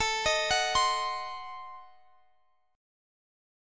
Listen to Adim7 strummed